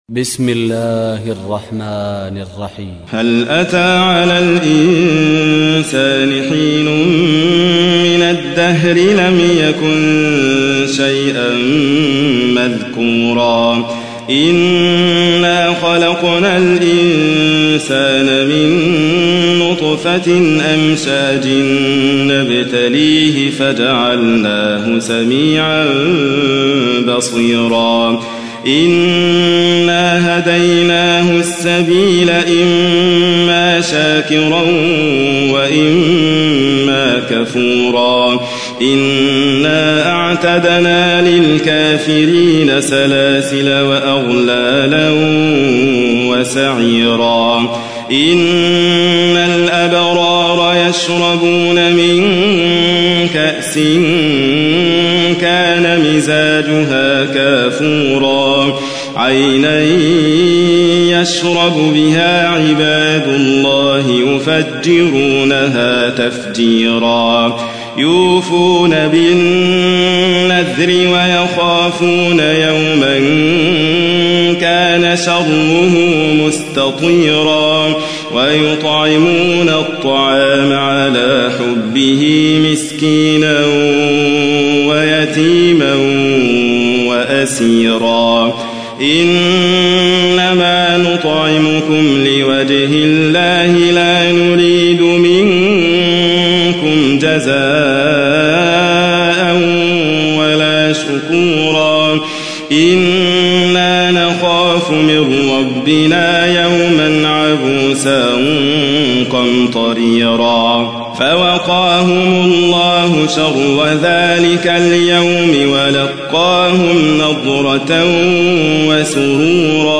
تحميل : 76. سورة الإنسان / القارئ حاتم فريد الواعر / القرآن الكريم / موقع يا حسين